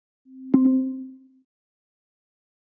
Системные звуки Apple iMac и MacBook Pro и Air в mp3 формате
7. Звук включения Siri
imac-siri.mp3